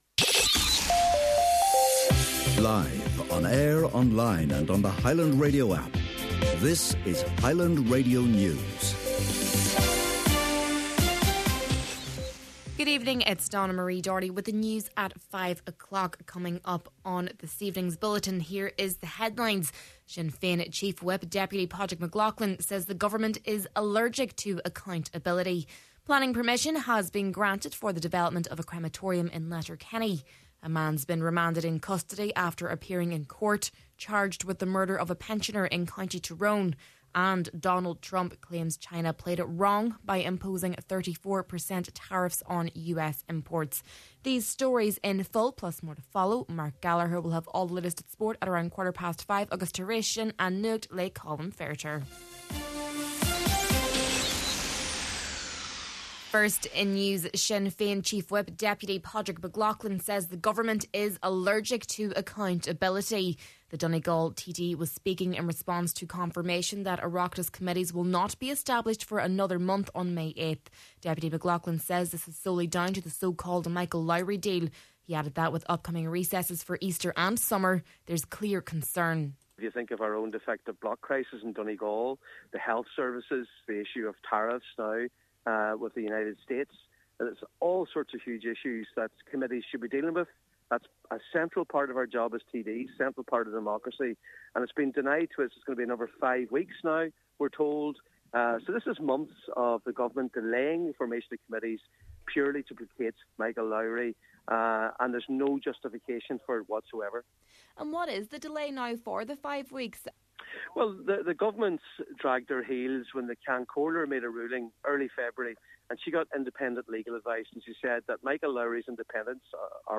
Main Evening News, Sport, An Nuacht and Obituaries – Friday, April 4th